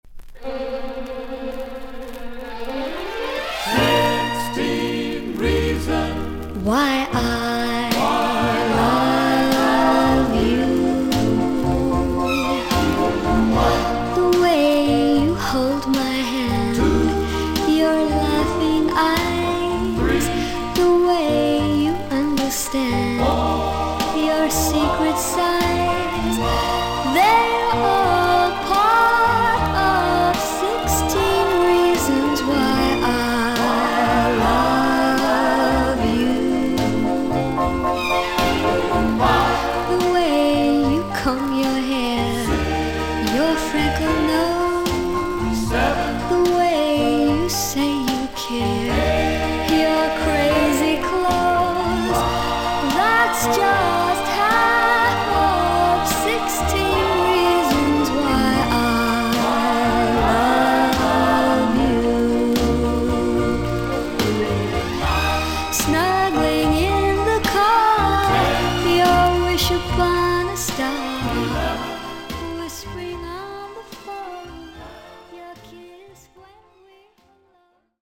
キュートなロリータ・ヴォイス。
VG++〜VG+ 少々軽いパチノイズの箇所あり。クリアな音です。